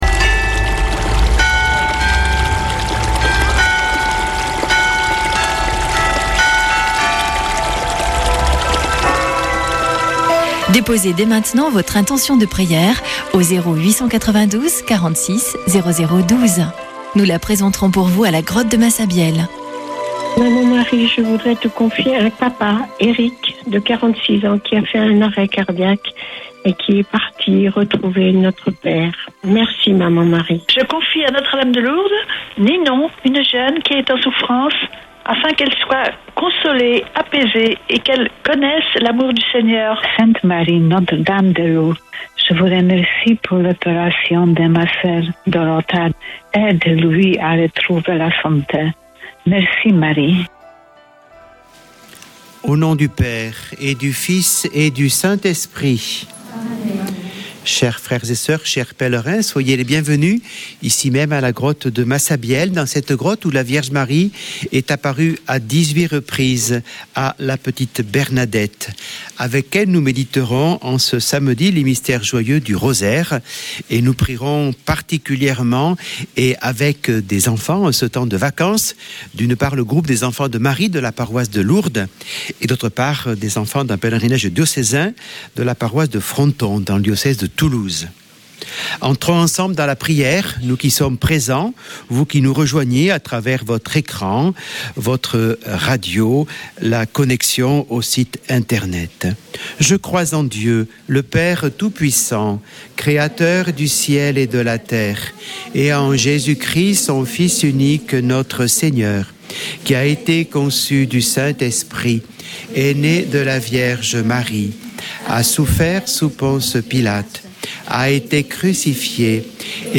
Chapelet de Lourdes du 21 févr.
Une émission présentée par Chapelains de Lourdes